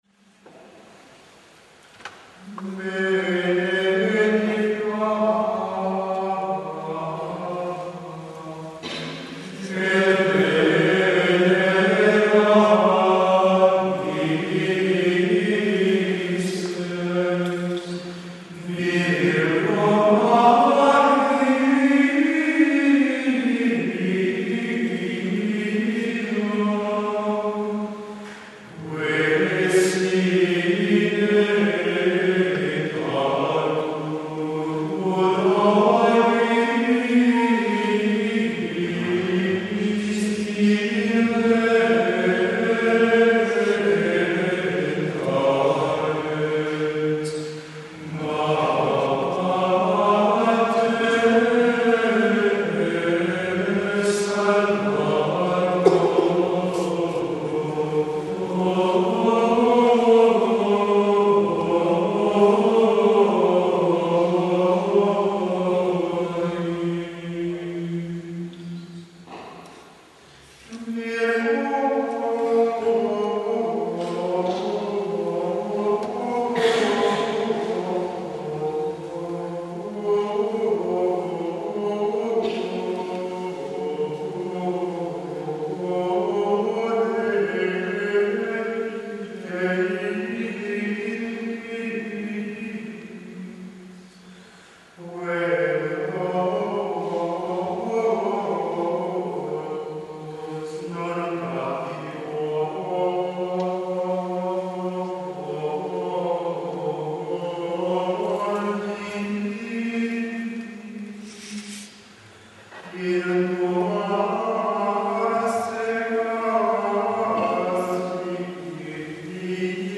We hope you enjoy this concert that our friars - primarily those who are studying for the priesthood - recently performed in the town of Comacchio, Italy, at the shrine of Our Lady of the Royal Hall, or "Santa Maria in Aula Regia" in Italian.